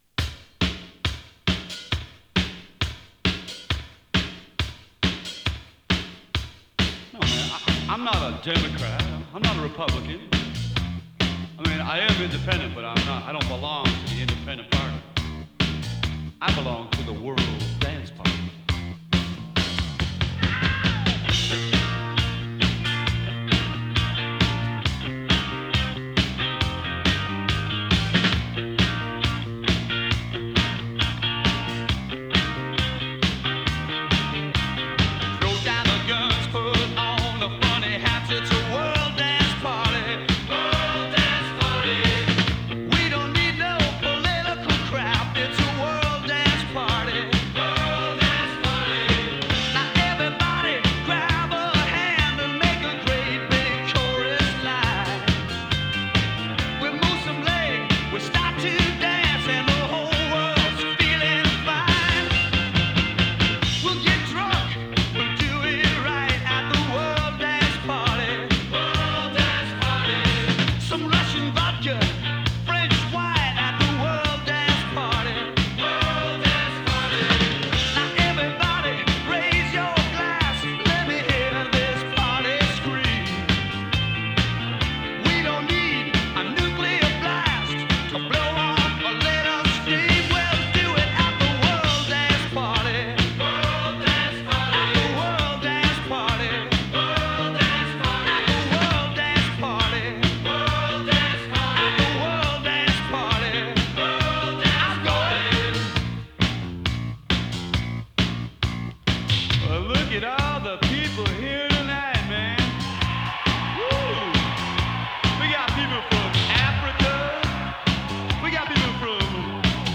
A面は80sらしいポップ・ナンバー。